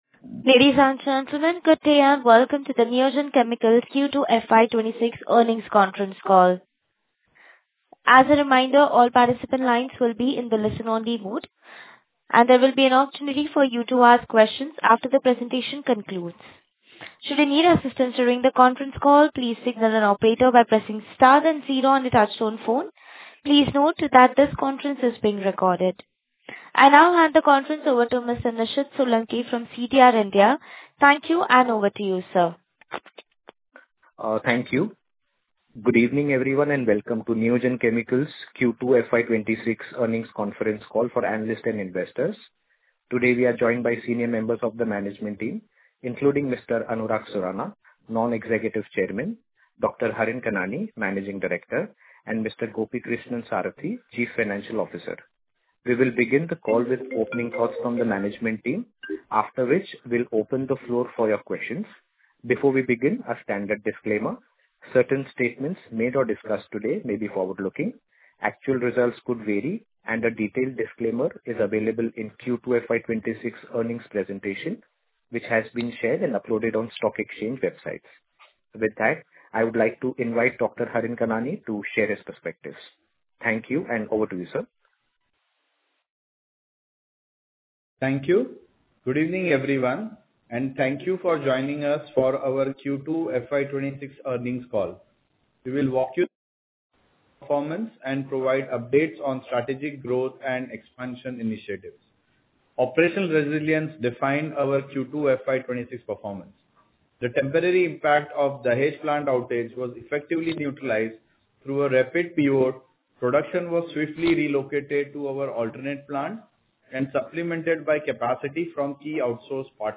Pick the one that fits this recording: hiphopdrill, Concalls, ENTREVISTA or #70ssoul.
Concalls